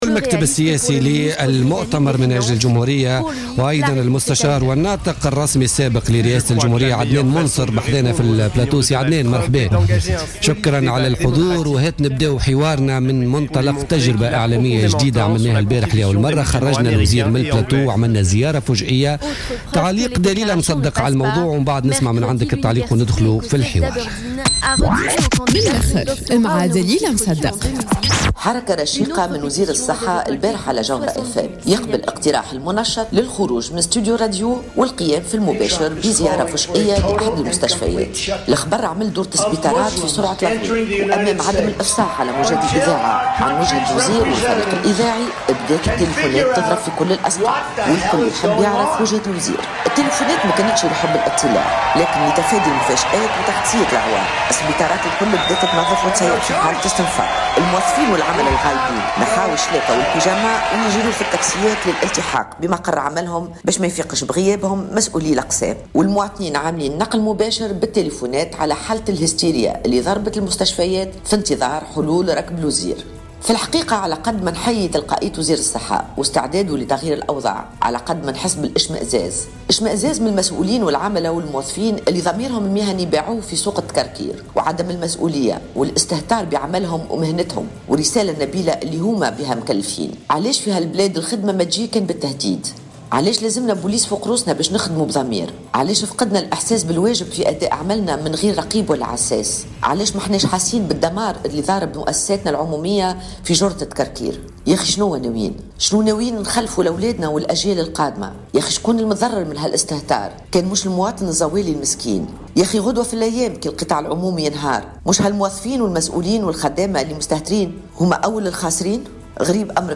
أكد الناطق الرسمي بإسم حراك شعب المواطنين عدنان منصر ضيف بوليتيكا اليوم الجمعة 11 ديسمبر 2015 أن السياق الذي جرت فيه الانتخابات وتشكيل التحالف الحكومي وبناء التحالف البرلماني هو حركية كاملة كان هدفها حكم البلاد دون معارضة وفق قوله.